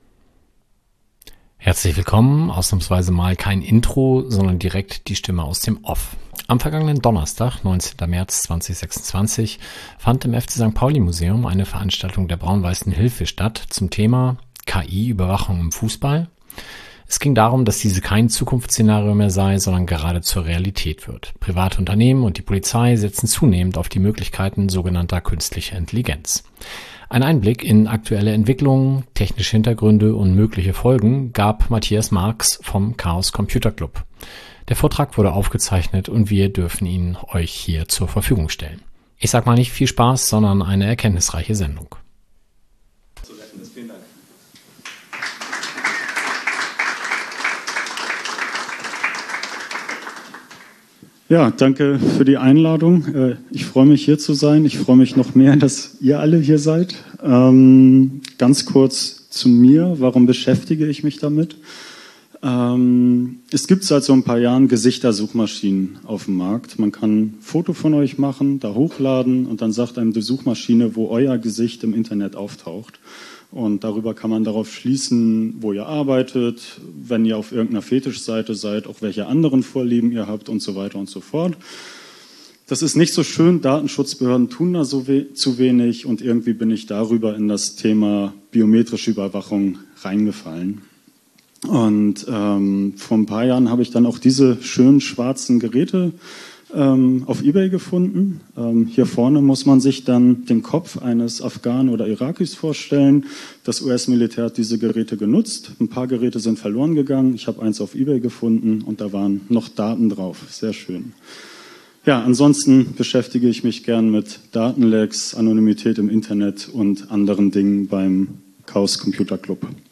Dieser Vortrag wurde aufgezeichnet und darf von uns hier veröffentlicht werden.